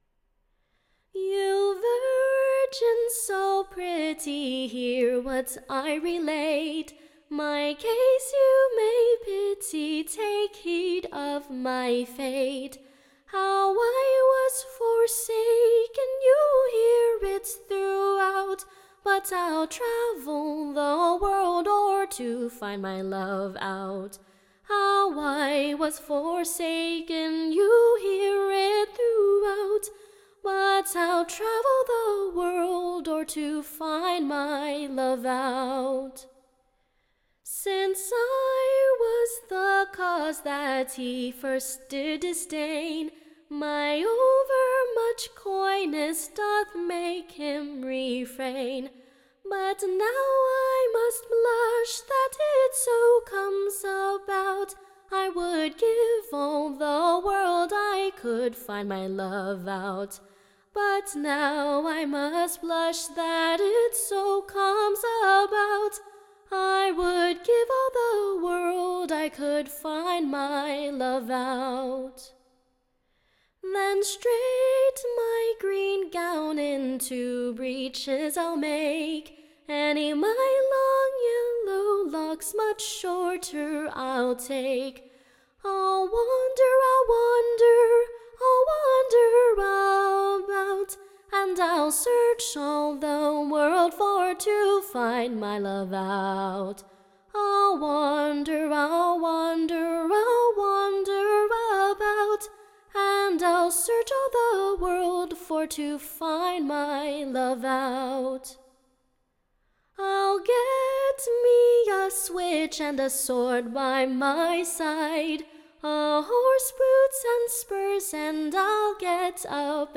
Recording Information Ballad Title The vvandering Virgin: / Or, The Coy Lass well Fitted.